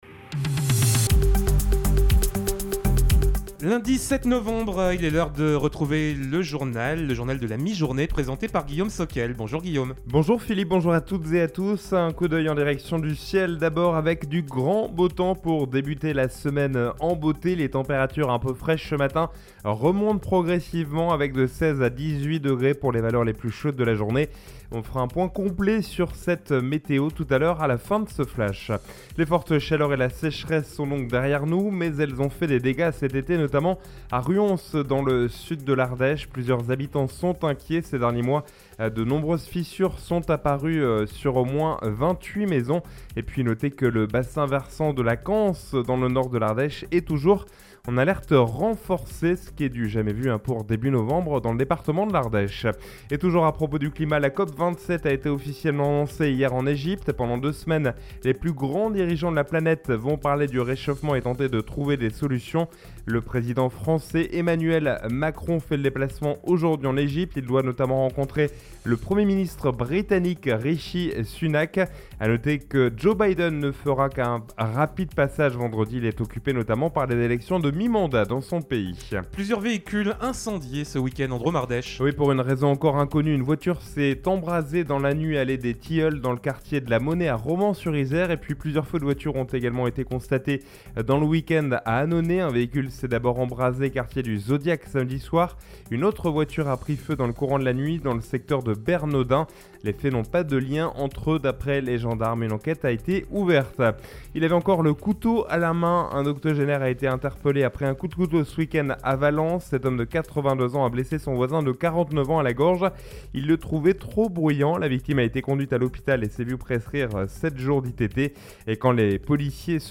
Lundi 07 novembre : Le journal de 12h